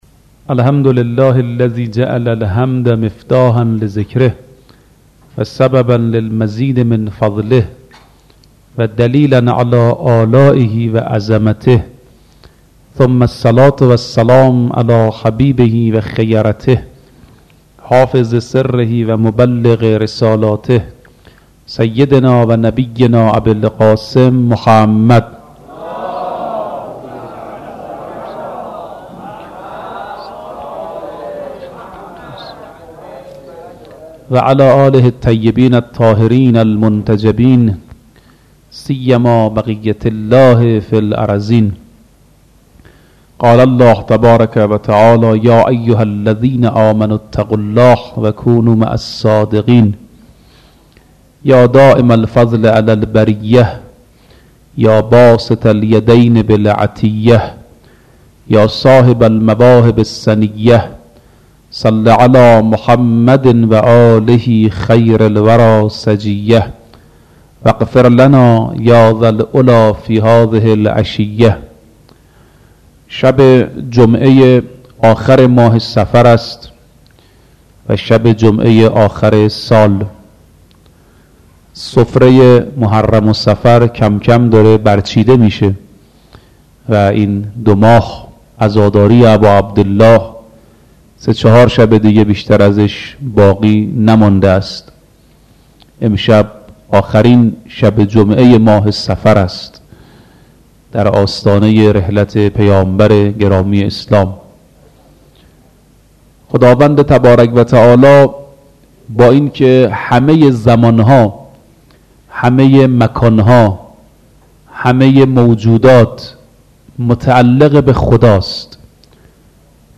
درمان با قرآن کریم (سخنرانی دکتر رفیعی)